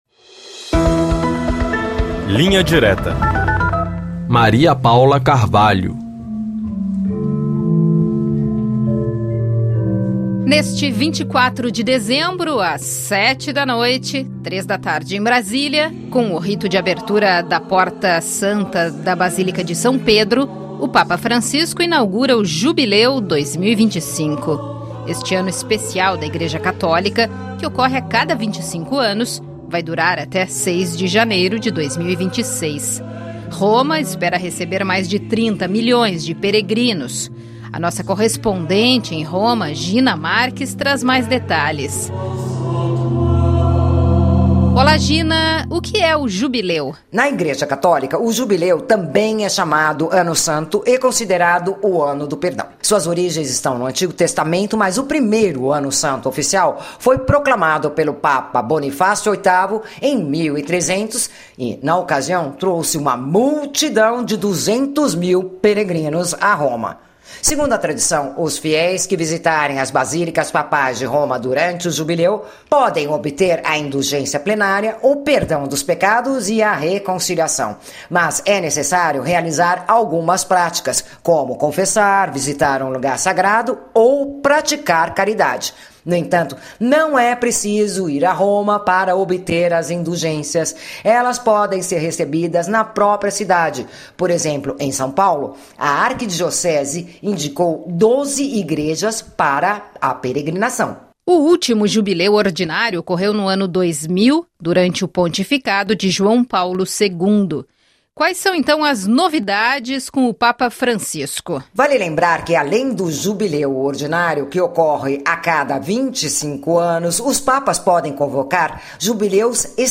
Bate-papo com os correspondentes da RFI Brasil pelo mundo para analisar, com uma abordagem mais profunda, os principais assuntos da atualidade.